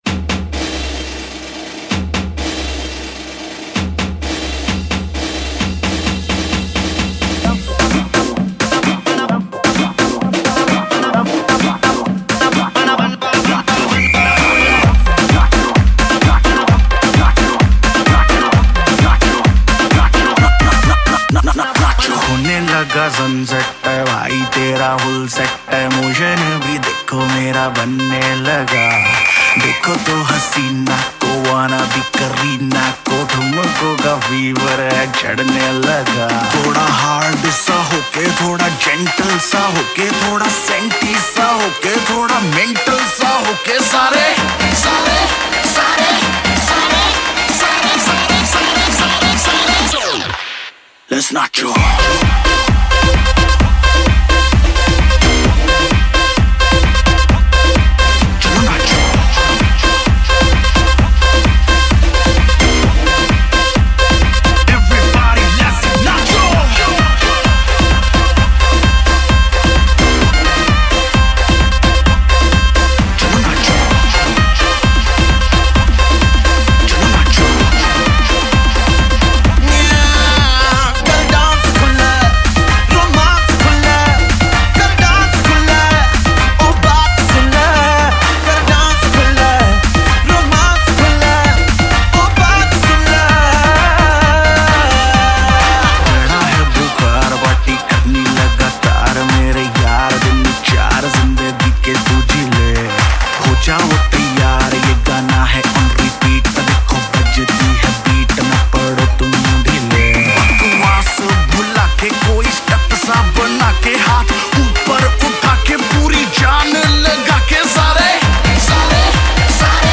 Hip Hop-Break Beat 320 Kbps.mp3